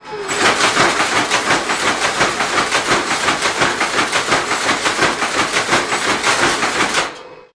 电子音
描述：我喜欢这个人用荷兰语说"电子声音的产生，这就是这个"。（Musée des instruments de musique, Bruxelles）
声道立体声